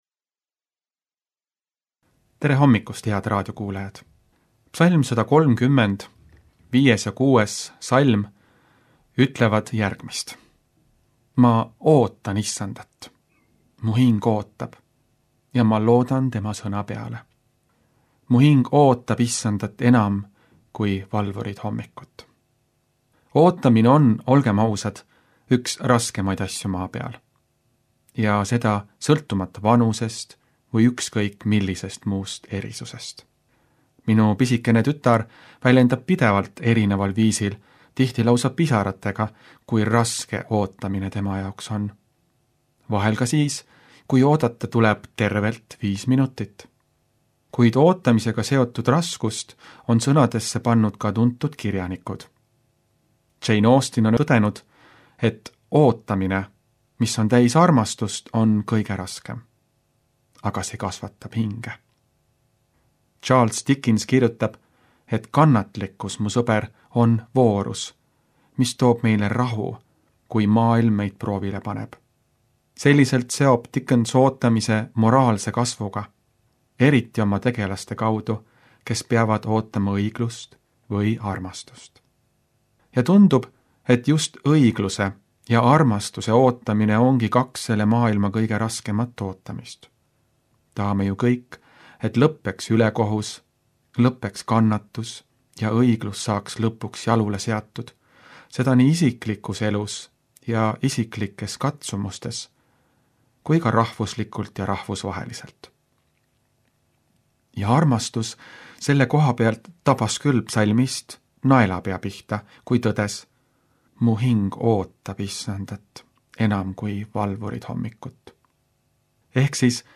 hommikupalvus Pereraadios 04.11.2025